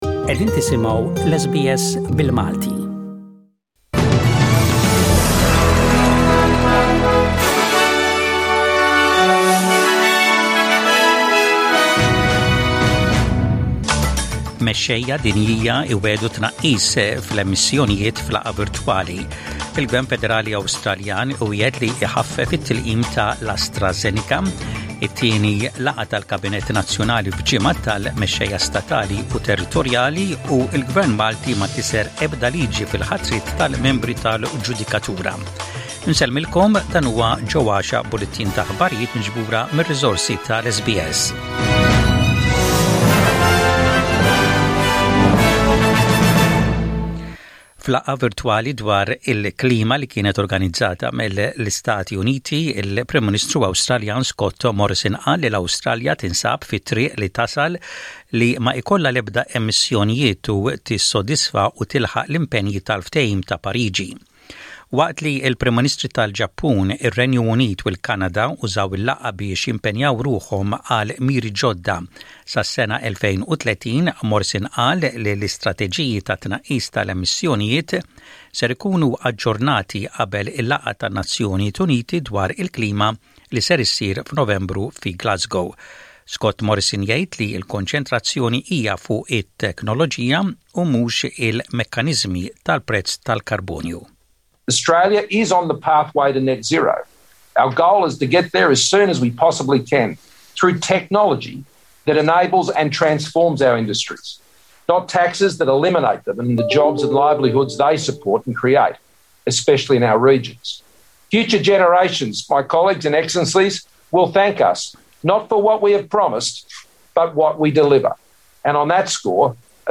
SBS Radio | Maltese News: 23/04/21